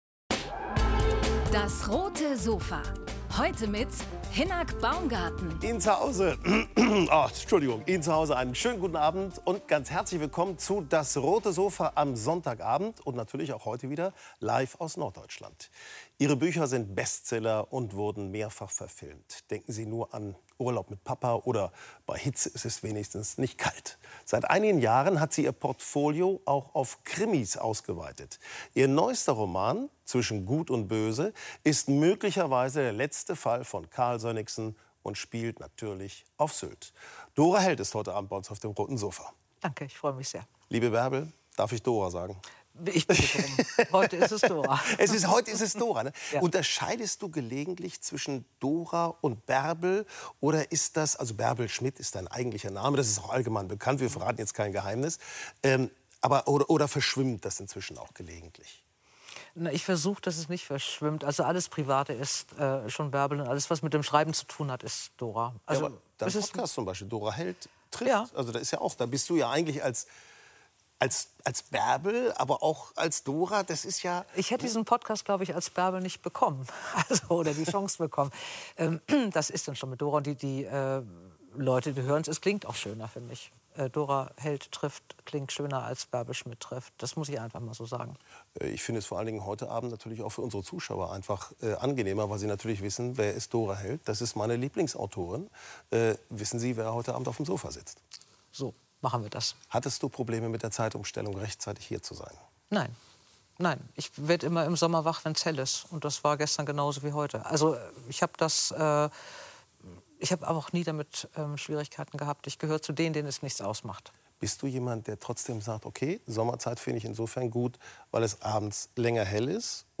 Mit Dora Heldt sprechen wir über ihr neuestes Werk und wollen natürlich wissen, wo die Ideen für ihre Bücher entstehen.